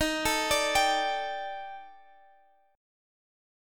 Listen to D#M7b5 strummed